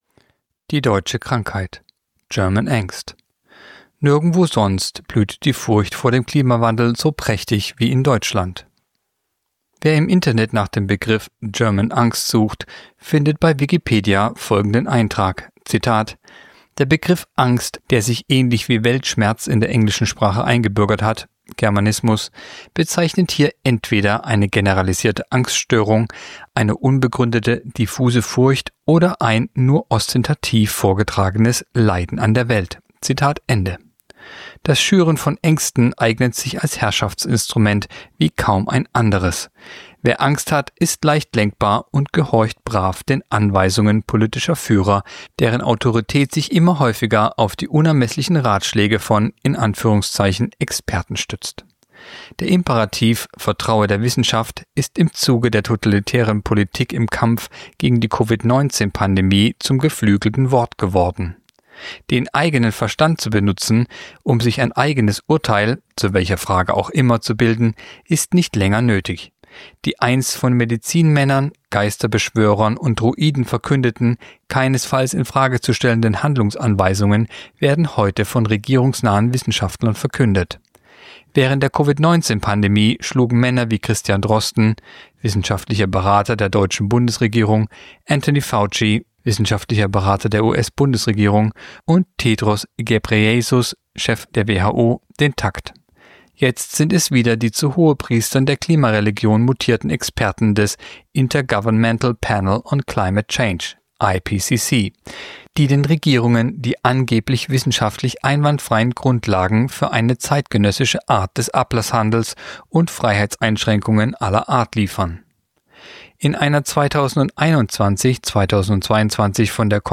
(Sprecher)